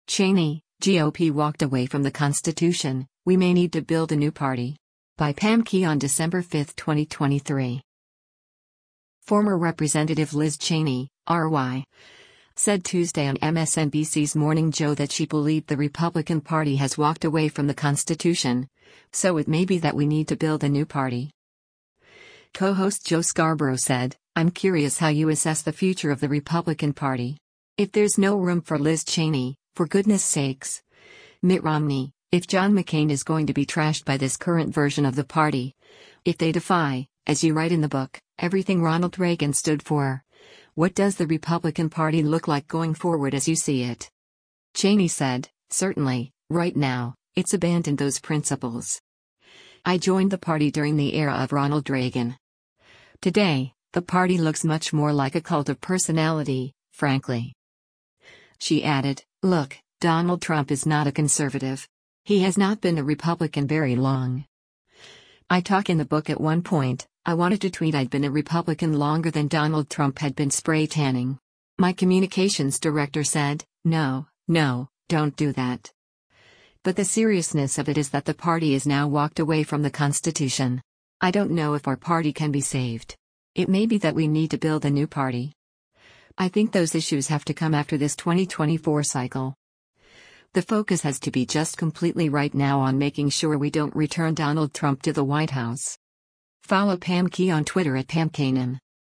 Former Rep. Liz Cheney (R-WY) said Tuesday on MSNBC’s “Morning Joe” that she believed the Republican Party has walked away from the Constitution, so it “may be that we need to build a new party.”